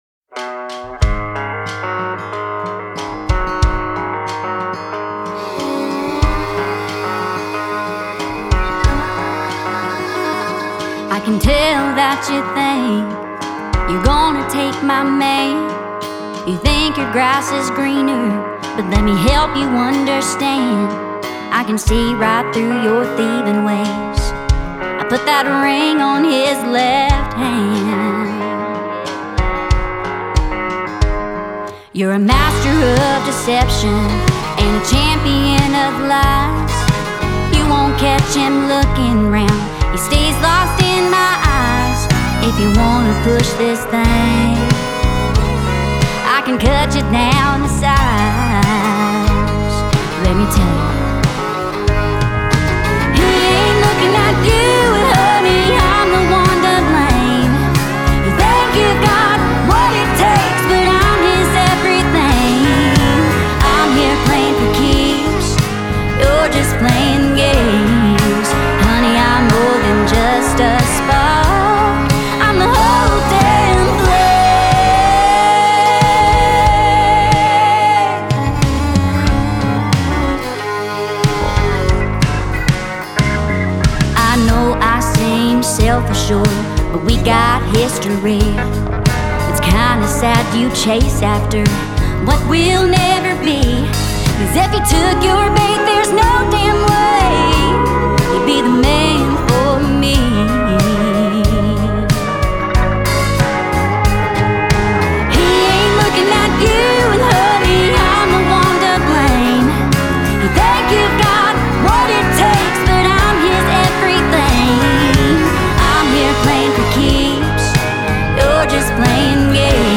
With catchy guitar riffs and an extremely contagious melody
powerful and soulful vocals that only she can deliver
SONG GENRE – COUNTRY